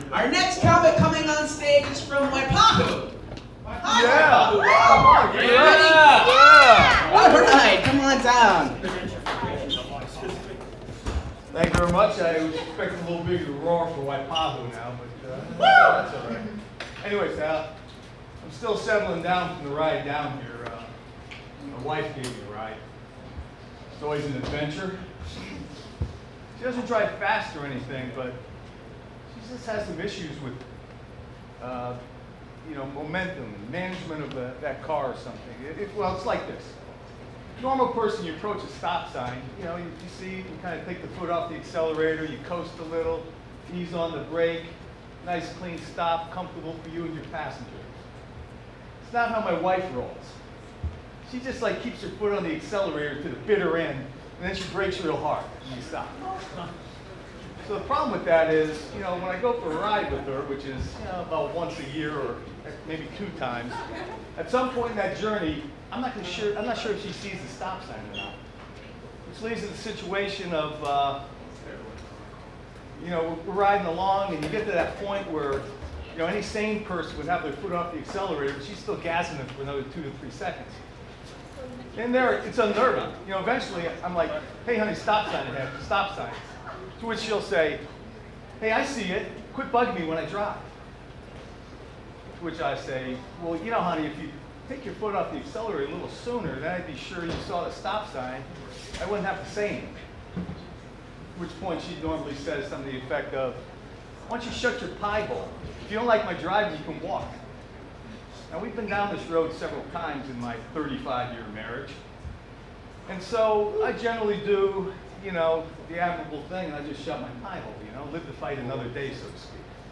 Stand-Up Comedy – Open Mic at the Downbeat Lounge – 6 Aug 2019